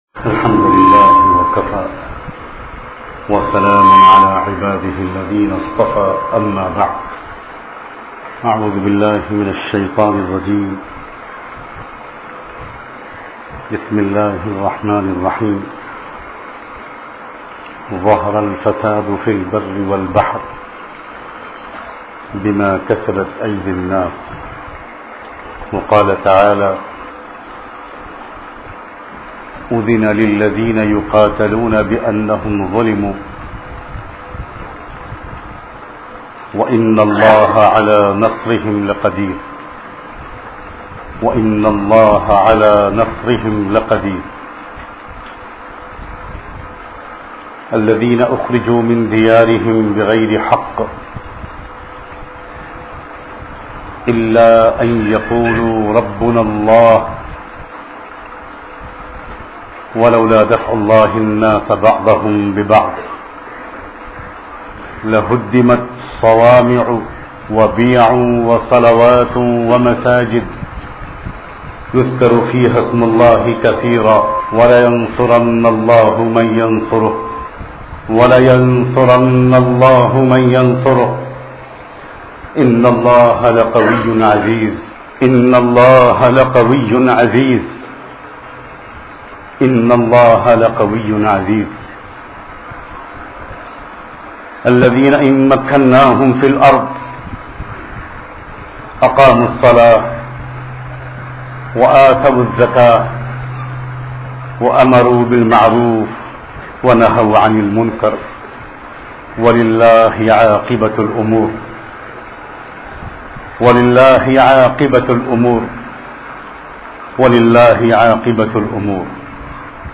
Aane waly halaat ki waja aamal bayan MP3